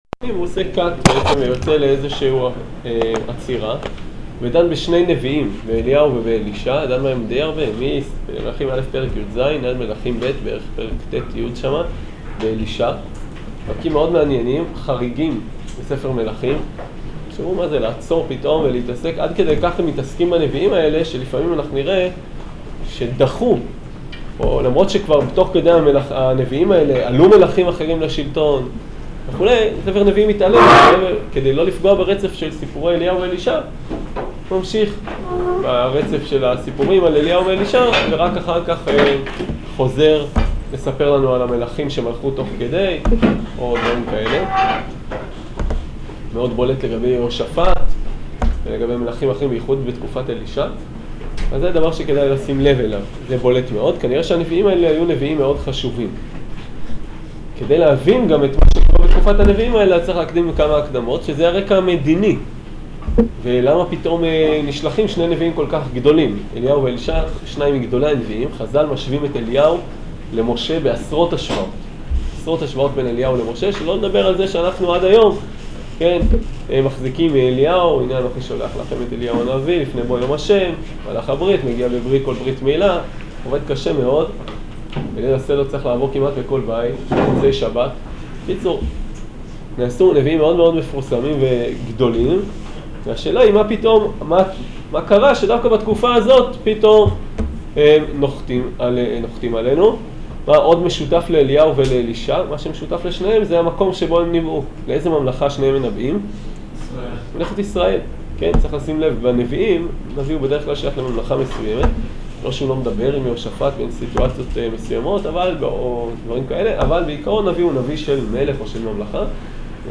שיעור פתיחה לאחאב